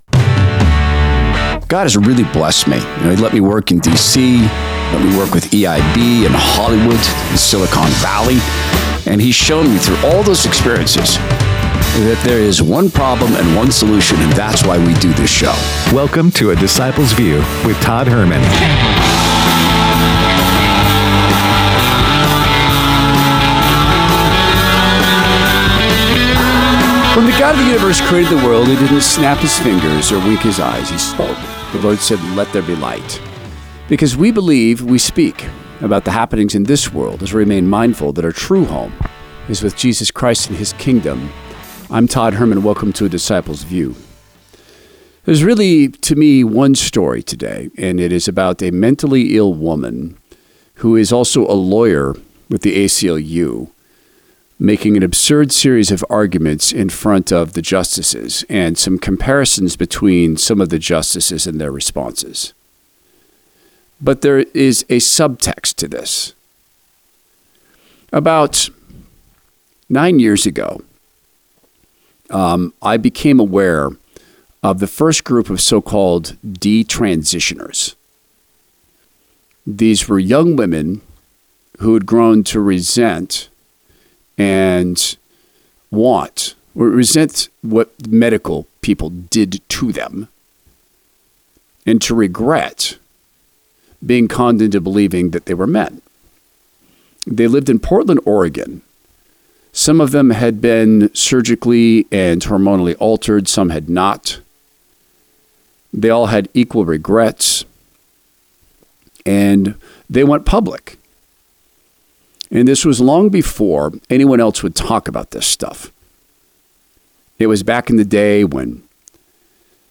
Supreme Court Oral Arguments In Tennessee Transgender Case